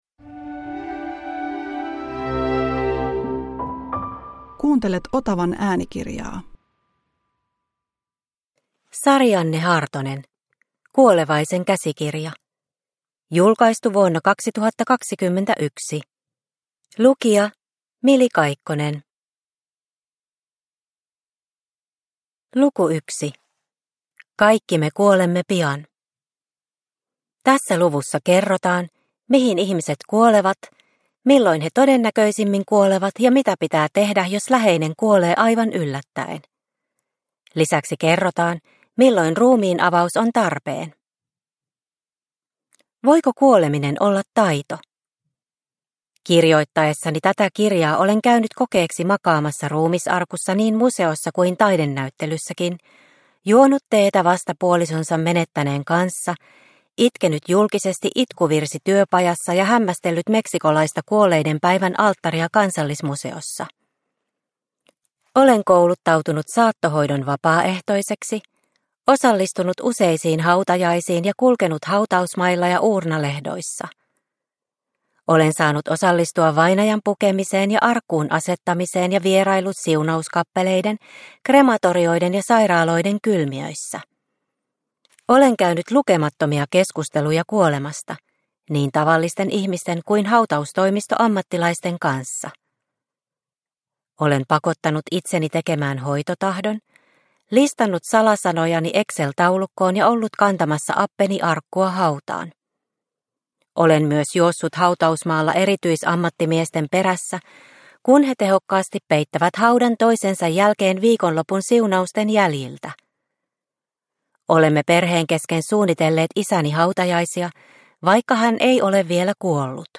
Kuolevaisen käsikirja – Ljudbok – Laddas ner